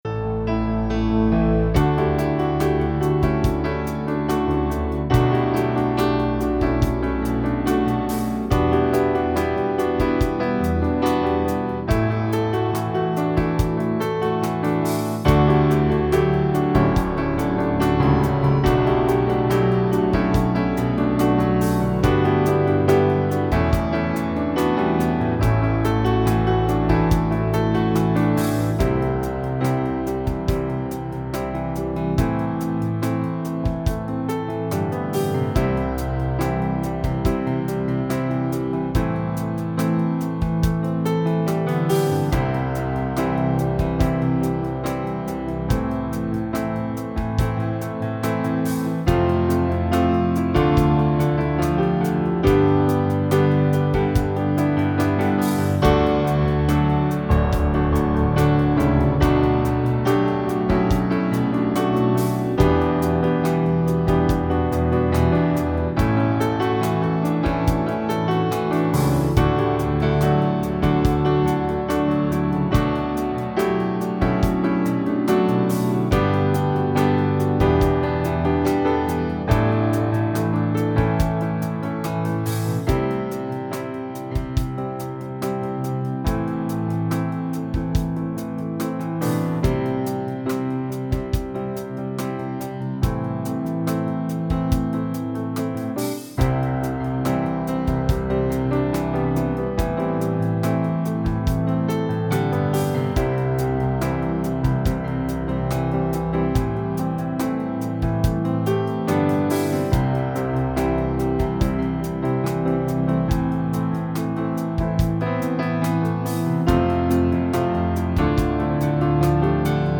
Übungsaufnahmen - Wonderful Dream
Runterladen (Mit rechter Maustaste anklicken, Menübefehl auswählen)   Wonderful Dream (Playback)
Wonderful_Dream__6_Playback.mp3